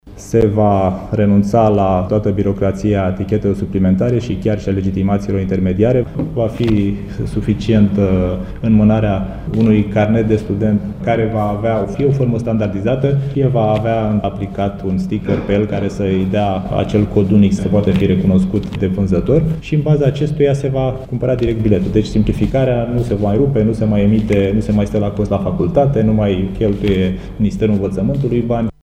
Așa a anunțat astăzi, Ministrul Transporturilor, Dan Costescu.